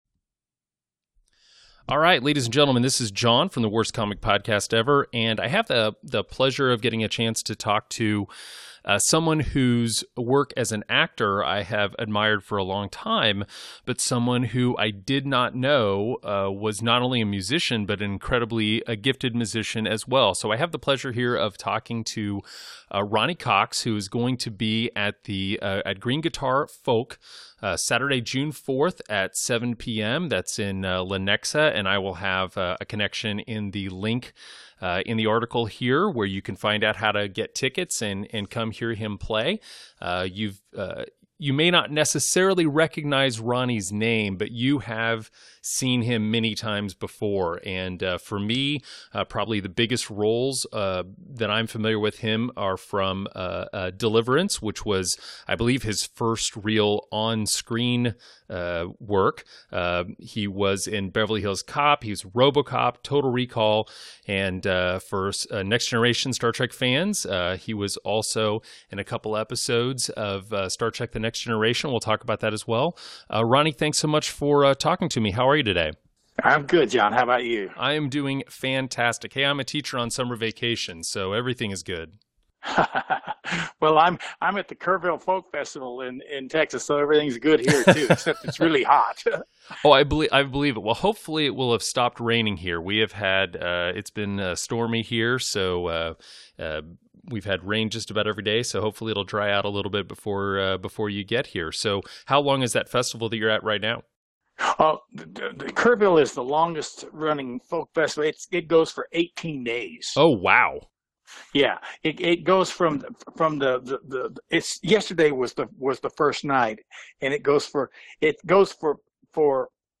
WCPE Interview – Ronny Cox
Earlier today, I had the pleasure of talking to an actor whose performances have thrilled movie and television audiences for years.